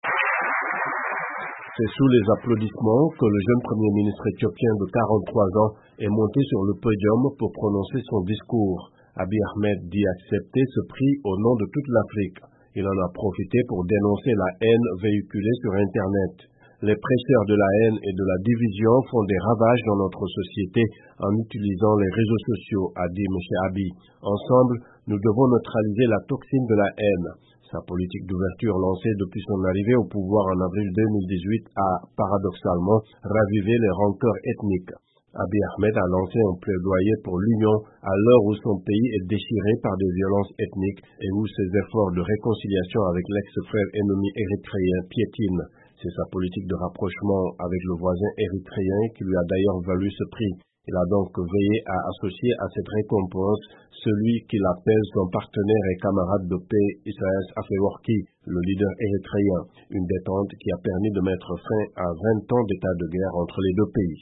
Prix Nobel de la paix : le discours du Premier ministre éthiopien
Le Premier ministre éthiopien Abiy Ahmed a reçu le prix Nobel de la paix à Oslo. Dans son discours, il a fustigé ceux qu’il a appelé les "prêcheurs de la haine".